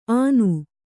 ♪ ānu